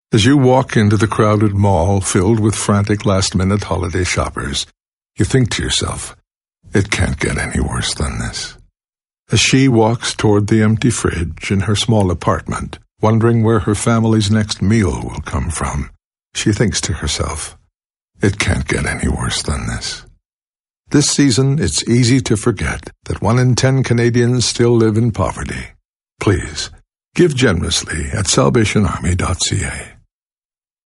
SilverPublic Service - Radio Campaign